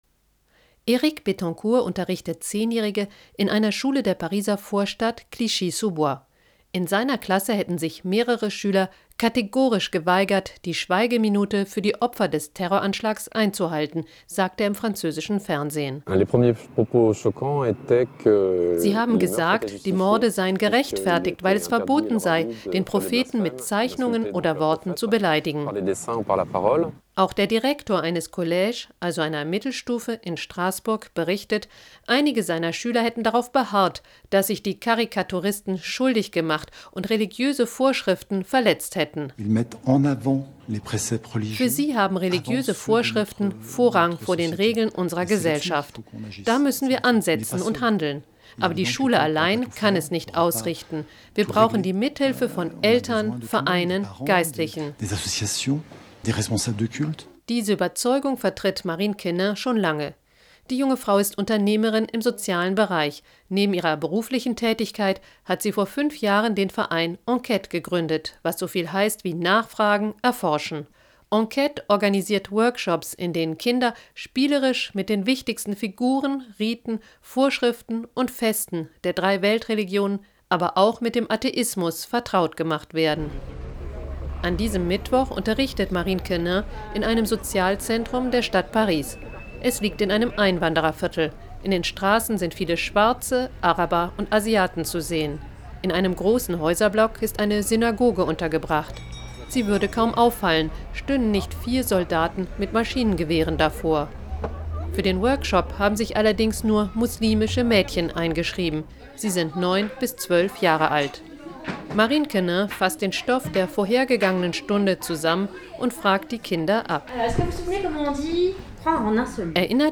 • Un reportage sur l’abord du fait religieux dans l’éducation, et notamment sur nos ateliers, sur la radio publique allemande (pour les germanophones).